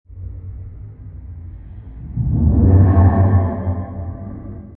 cave_monster.wav